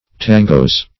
tangos - definition of tangos - synonyms, pronunciation, spelling from Free Dictionary
Tangos (-g[=o]z).